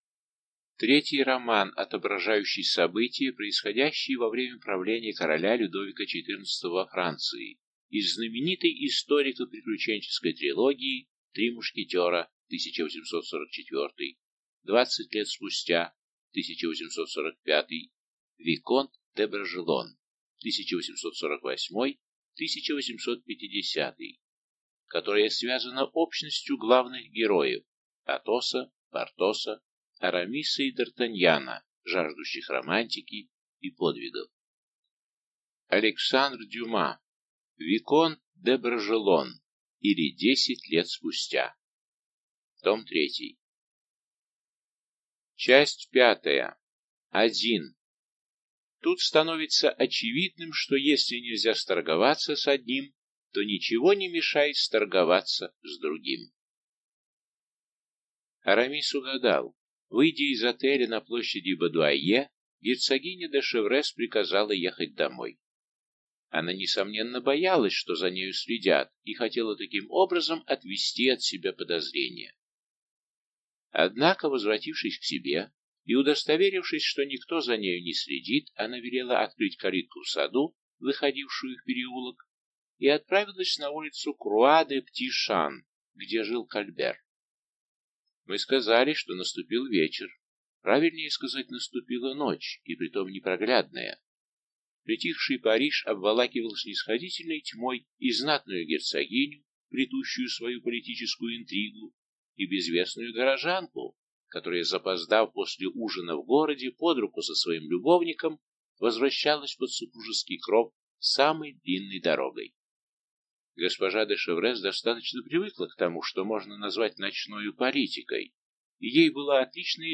Аудиокнига Виконт де Бражелон, или Десять лет спустя. Том 3 | Библиотека аудиокниг